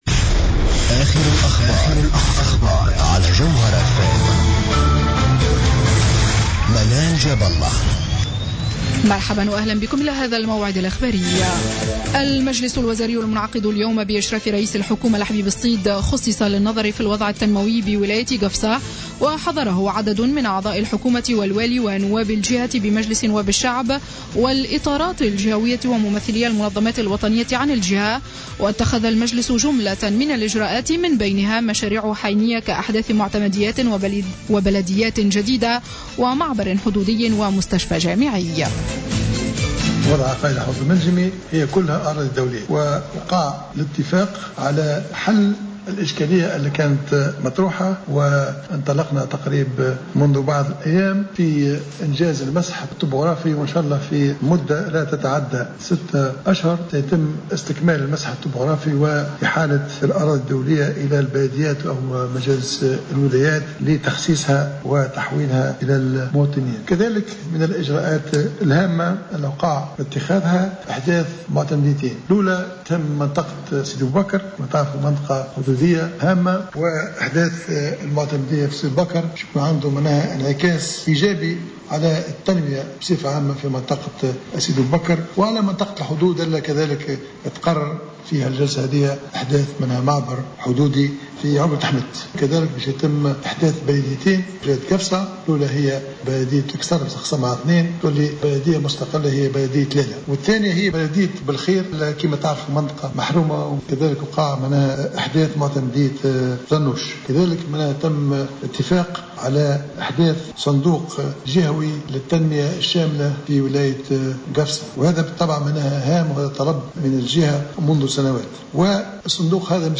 نشرة أخبار السابعة مساء ليوم الجمعة 15 ماي 2015